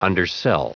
Prononciation du mot : undersell
undersell.wav